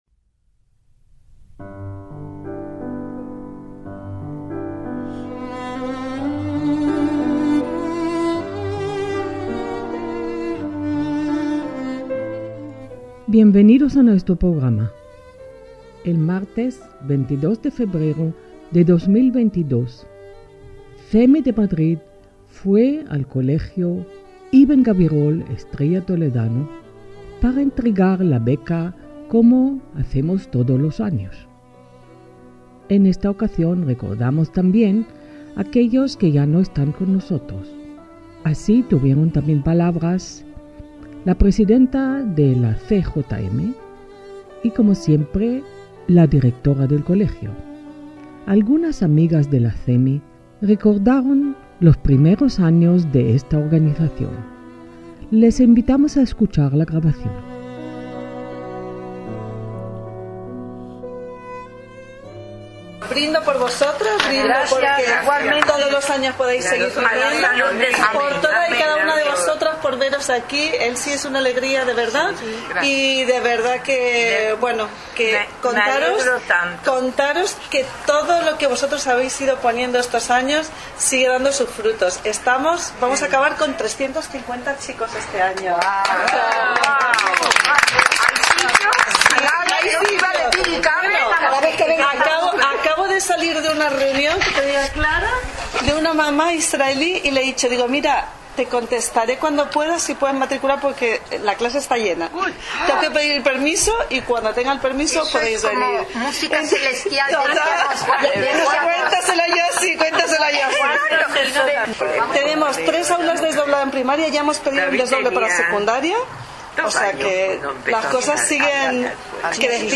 Las veteranas recordaron los primeros pasos del colegio y el principio de CEMI. Con emoción, guardamos un minuto de silencio, recordando los fundadores de ambas instituciones.